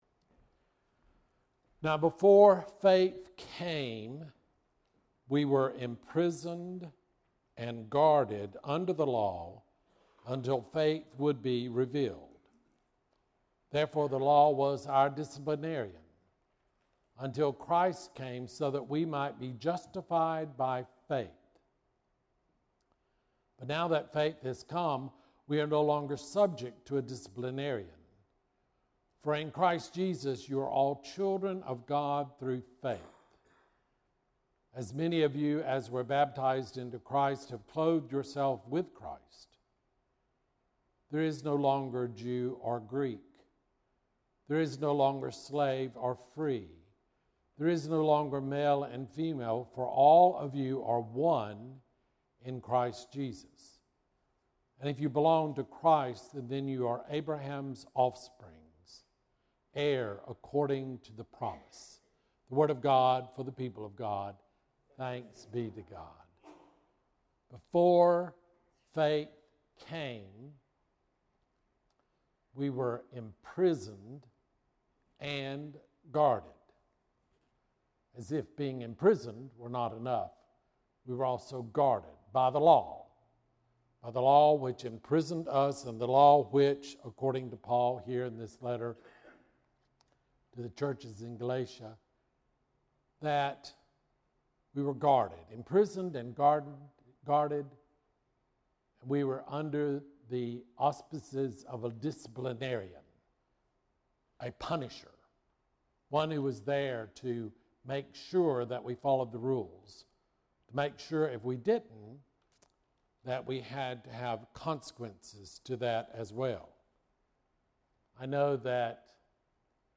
Bible Text: Galatians 3:23-29 | Preacher